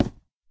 wood3.ogg